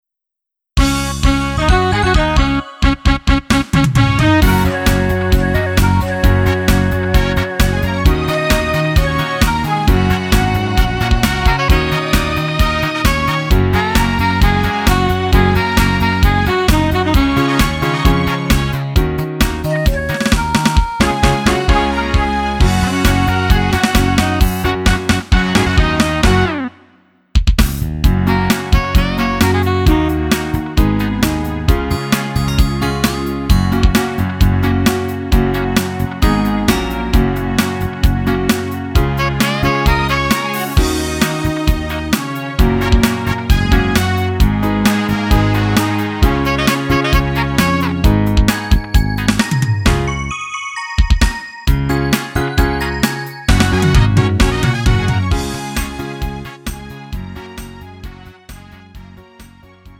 음정 -1키 3:37
장르 구분 Lite MR